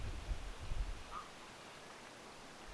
jalohaikara?
onko_tuo_sen_jalohaikaran_pieni_aani.mp3